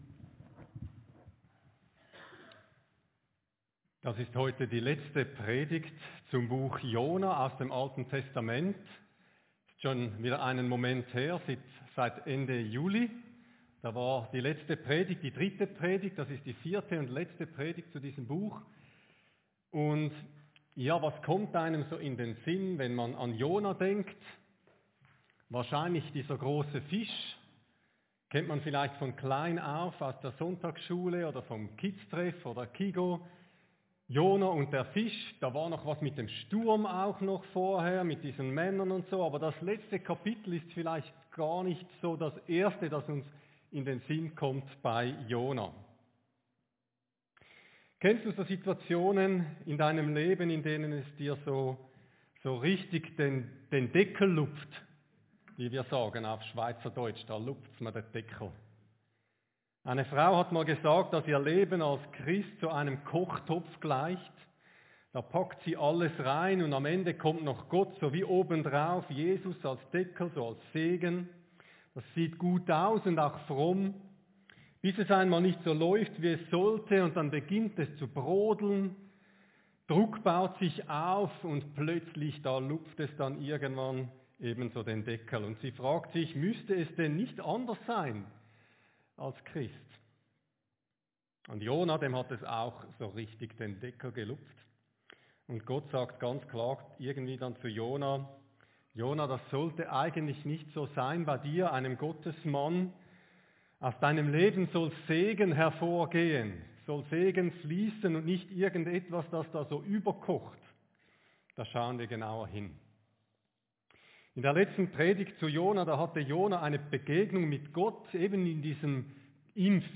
Predigt-25.8.24.mp3